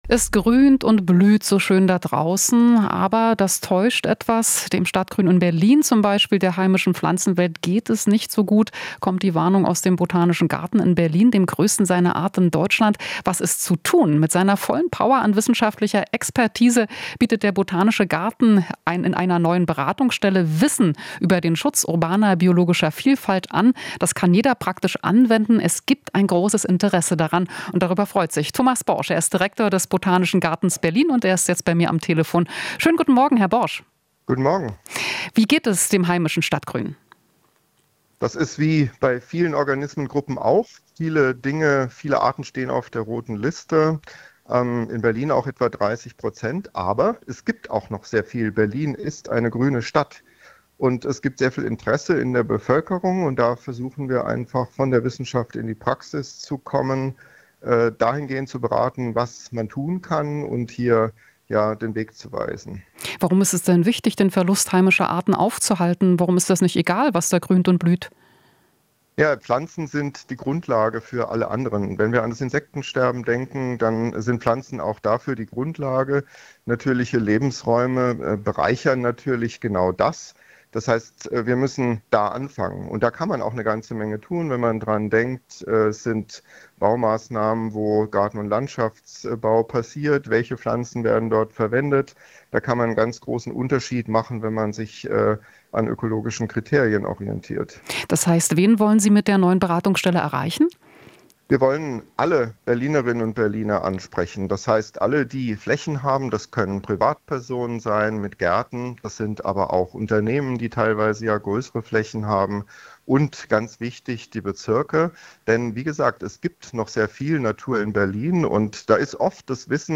Interview - Neue Beratungsstelle zur Biodiversität am Botanischen Garten